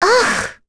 Rehartna-Vox_upset.wav